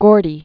(gôrdē), Berry, Jr. Born 1929.